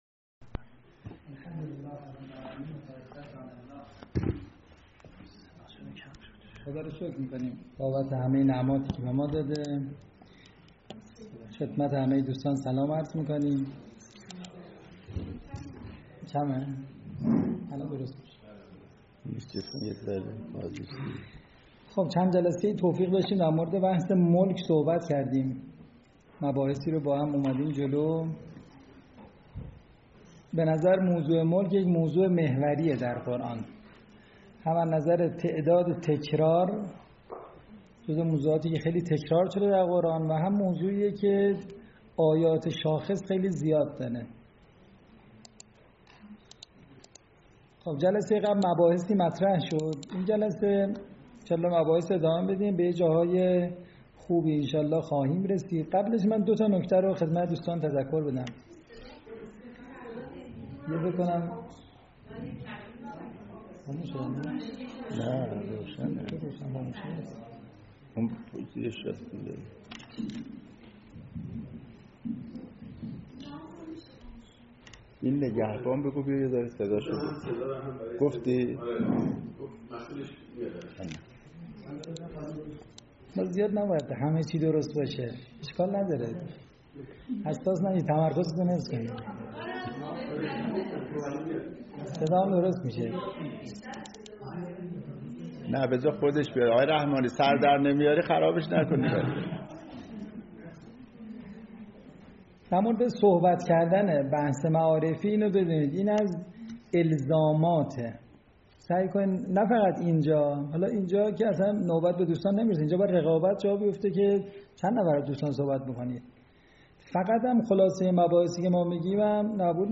فایل صوتی سخنرانی